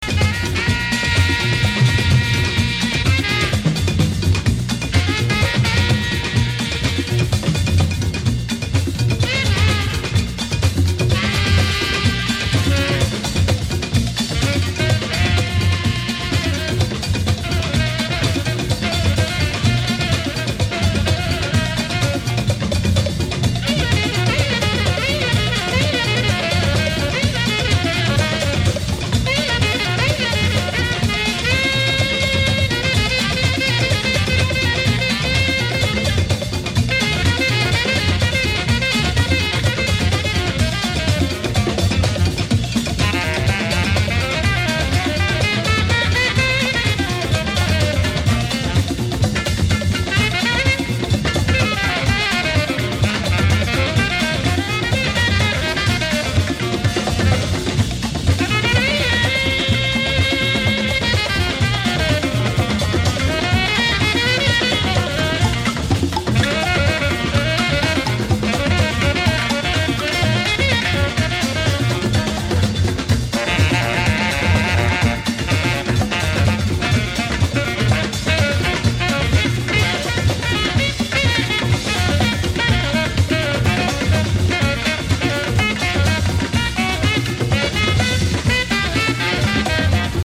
[AFRO&LATIN]